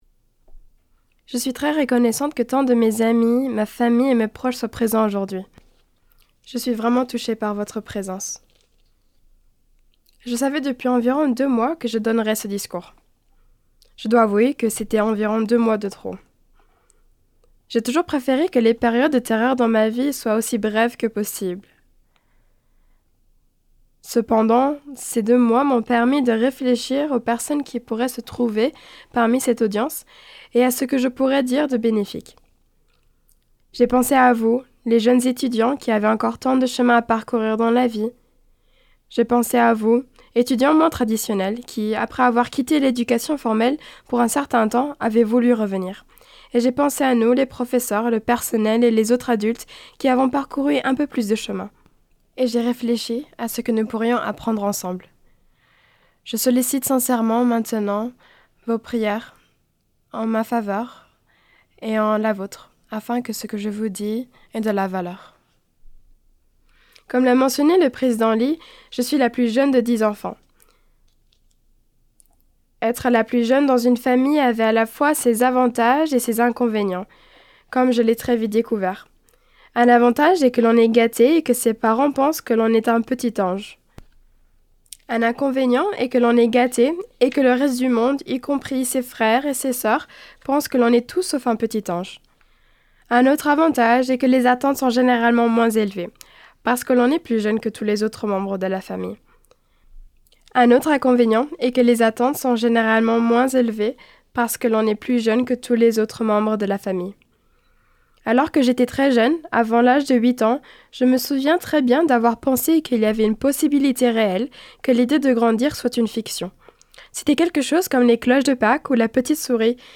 Réunion spirituelle